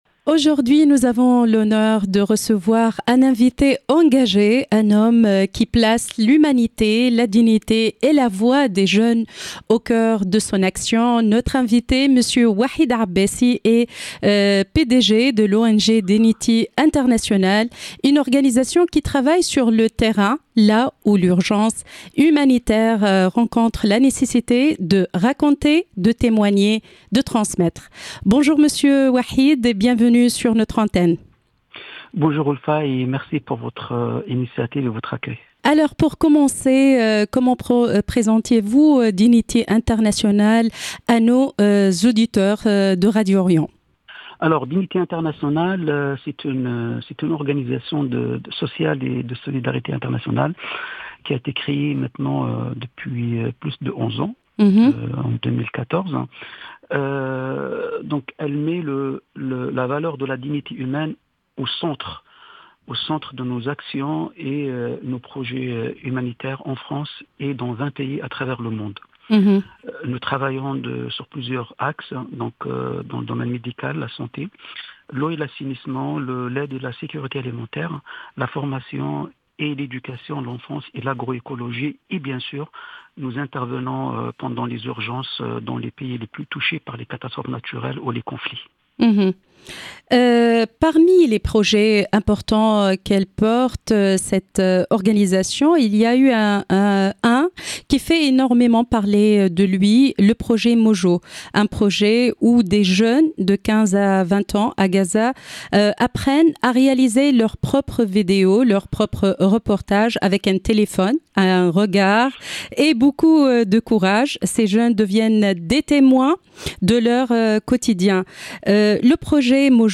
AGENDA CULTUREL